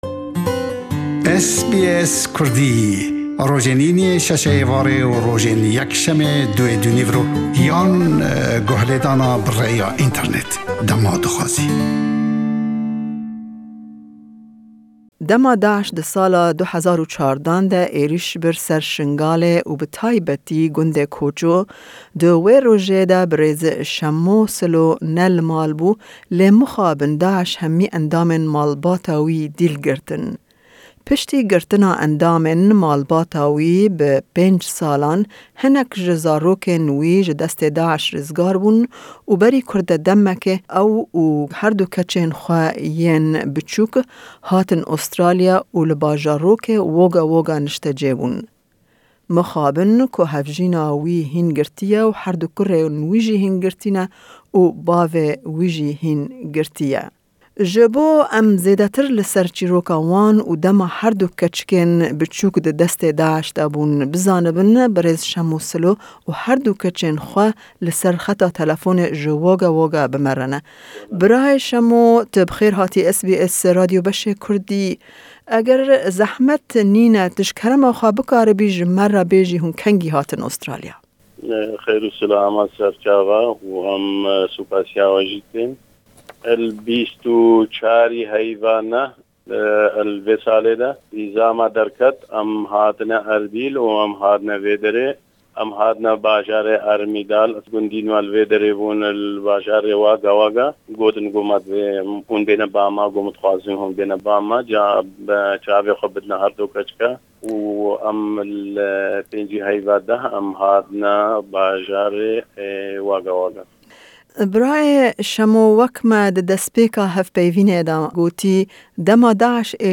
Di vê hevpeyvînê de